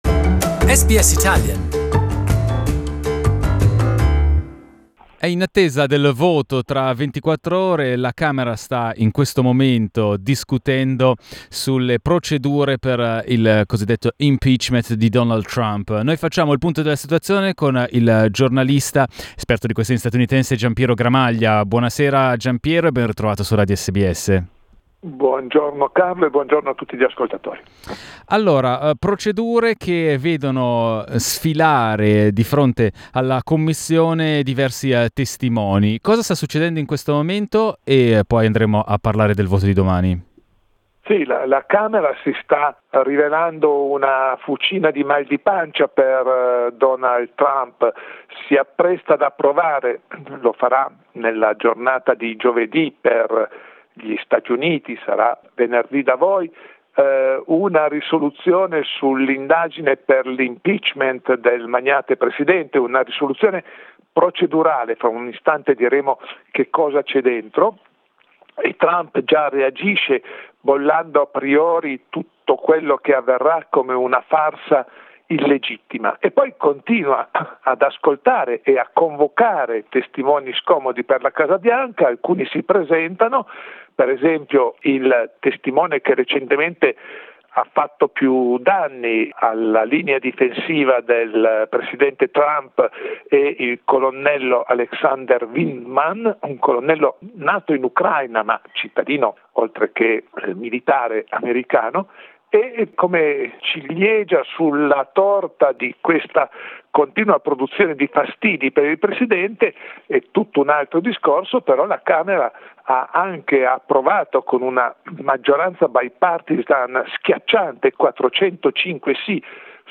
House investigators are summoning top Trump officials, including former national security adviser John Bolton, to testify next week as the impeachment probe pushes ever deeper into the White House. Journalist